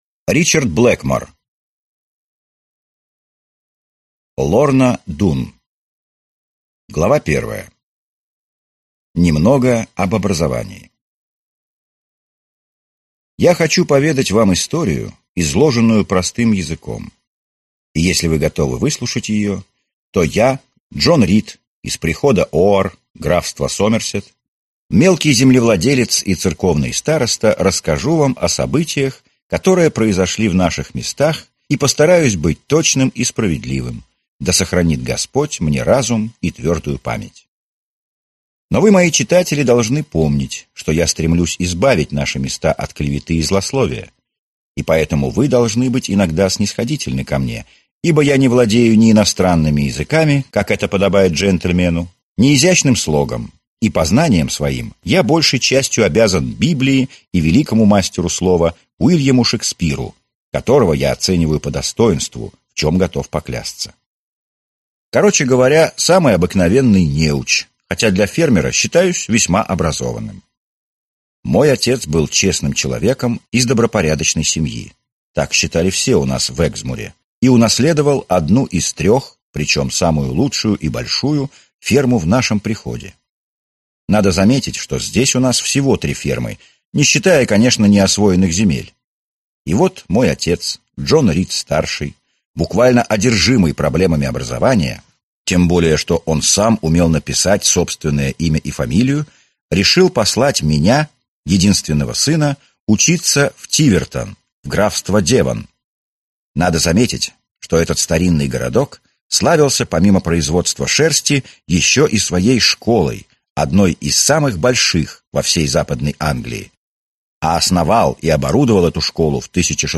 Аудиокнига Лорна Дун | Библиотека аудиокниг